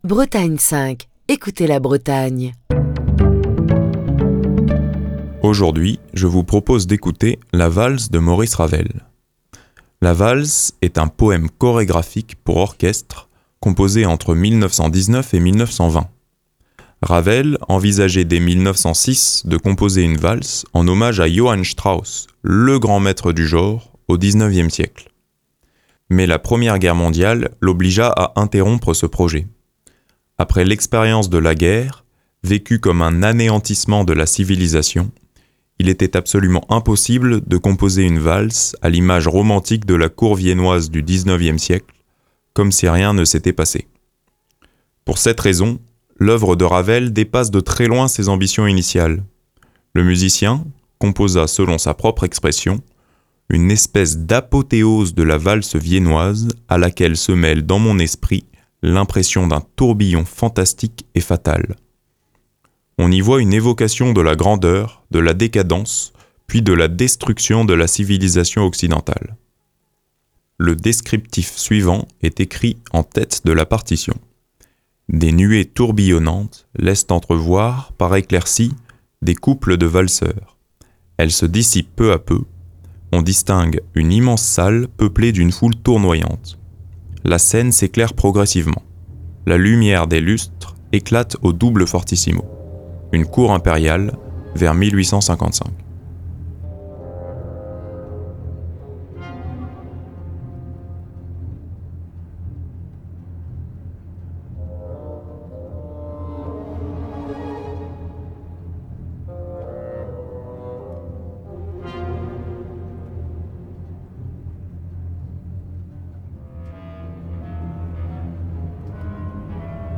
La Valse est un poème chorégraphique pour orchestre composé entre 1919 et 1920.